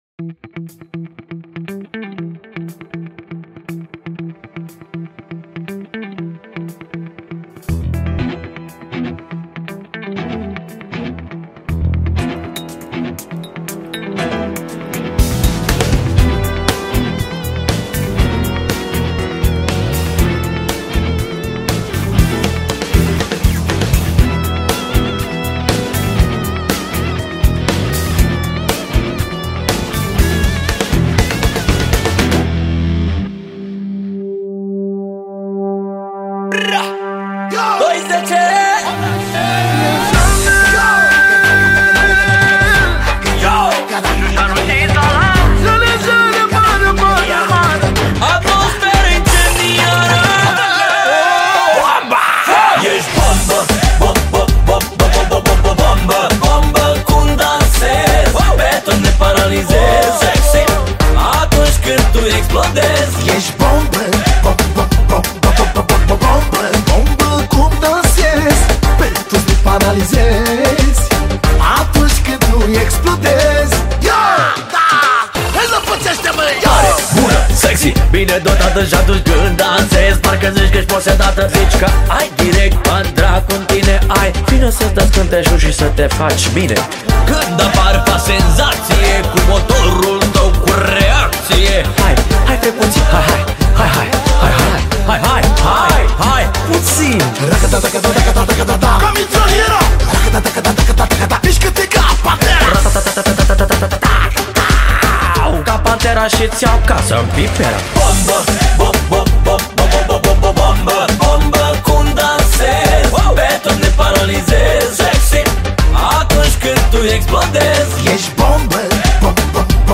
Categoria: Manele New-Live